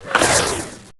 Heroes3_-_War_Unicorn_-_ShootSound.ogg